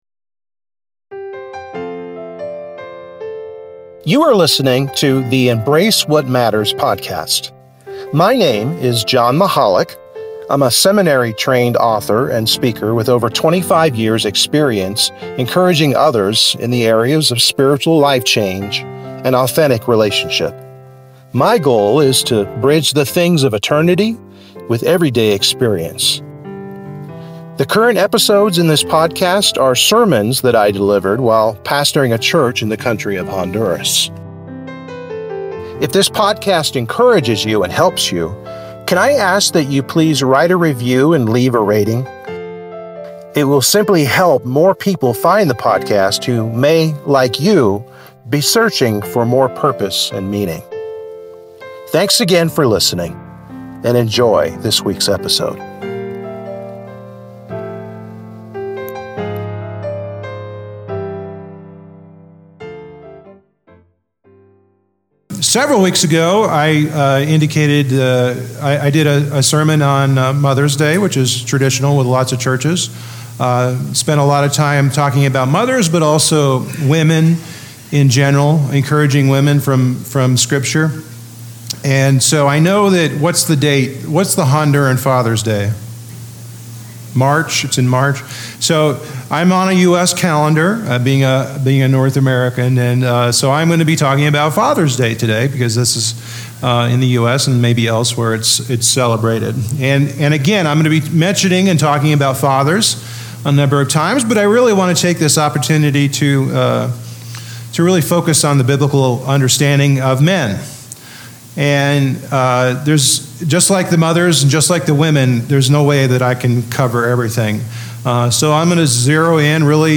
(sermon recorded in 2018).
Mark 9:23 Service Type: Sunday Morning Worship